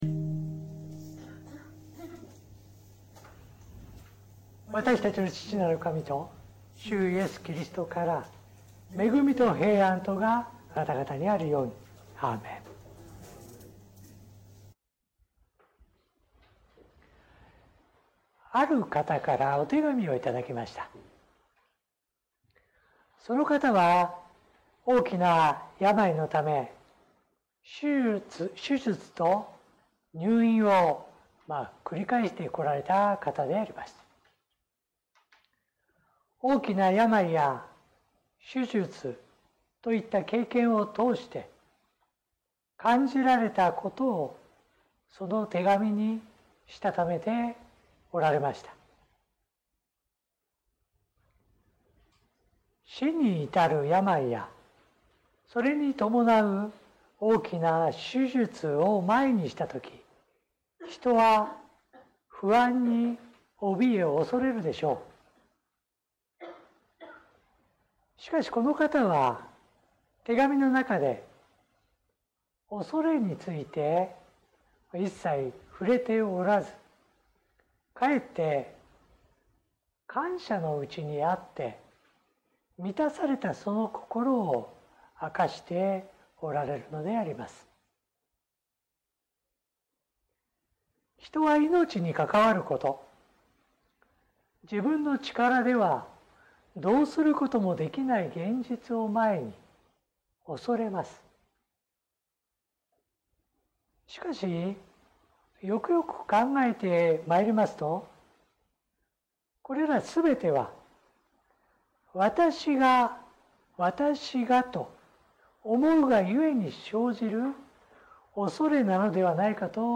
説教音声